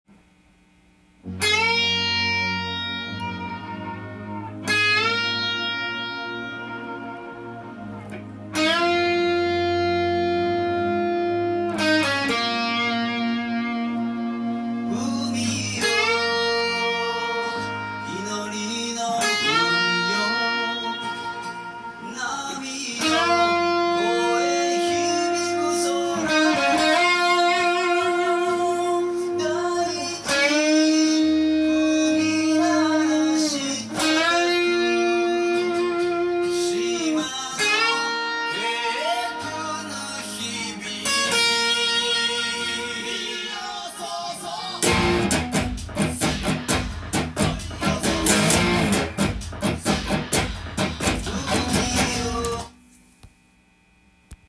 音源にあわせて譜面通り弾いてみました
沖縄の音楽らしい壮大な自然を感じる楽曲です。
エレキギターで弾けるようにチョーキングを多用してみました。